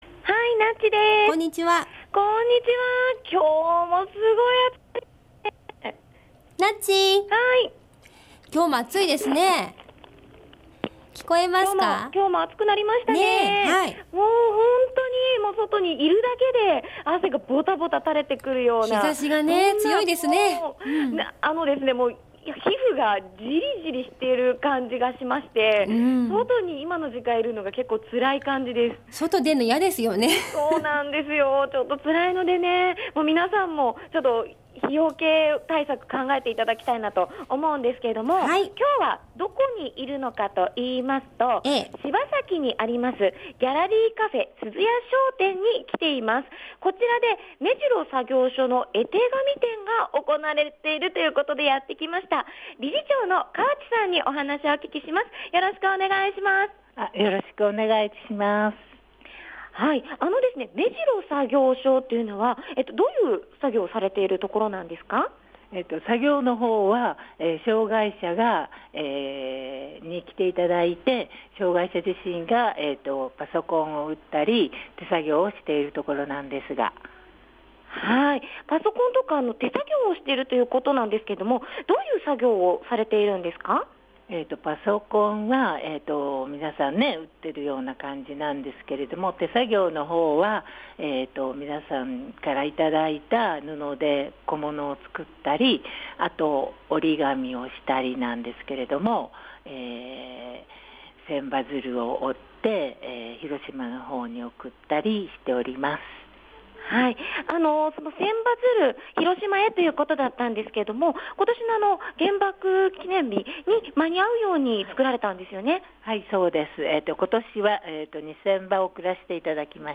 午後のカフェテラス　街角レポート
今日のレポートは ギャラリーカフェ鈴や商店で行われております
今日作業所の方も来て下さってお話してくださいました（＾－＾）